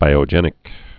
(bīō-jĕnĭk)